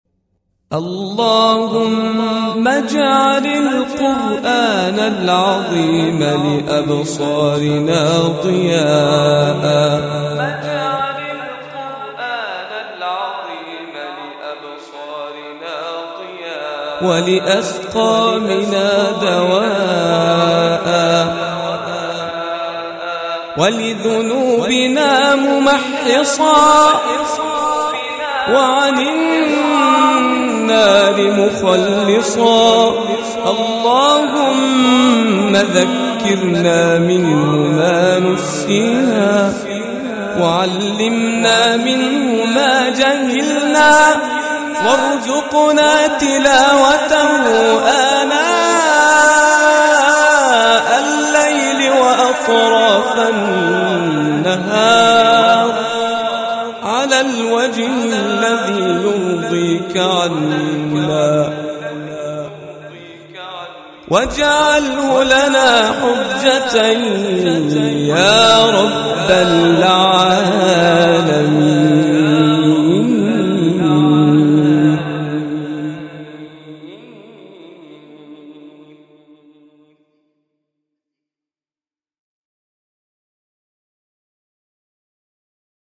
دعاء بصوت